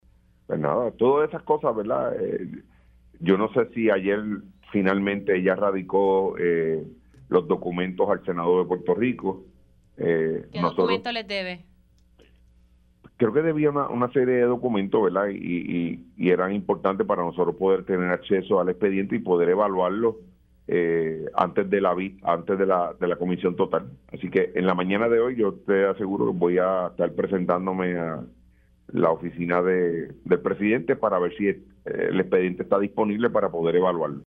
207-JUAN-OSCAR-MORALES-SENADOR-PNP-FERRAIOULI-TODAVIA-DEBE-ALGUNOS-DOCUMENTOS.mp3